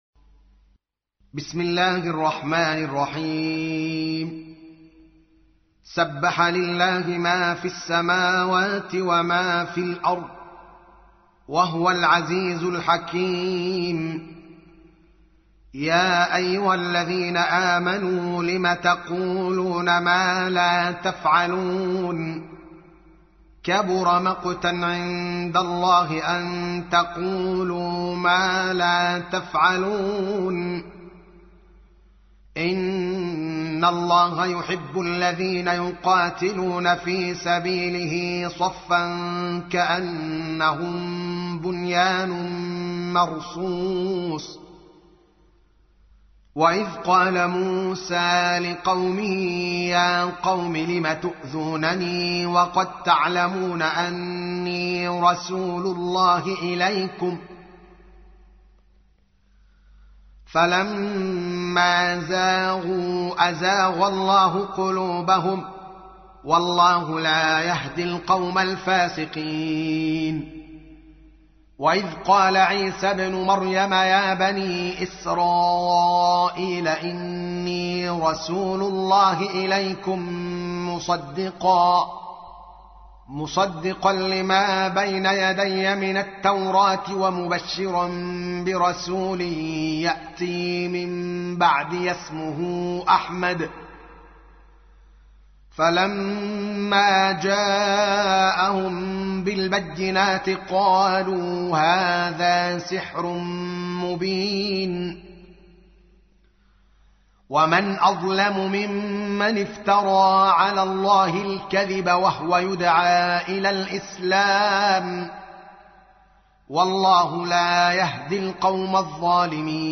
61. سورة الصف / القارئ
القرآن الكريم